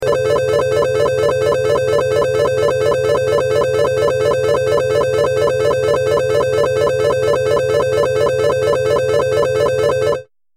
دانلود صدای هشدار 28 از ساعد نیوز با لینک مستقیم و کیفیت بالا
جلوه های صوتی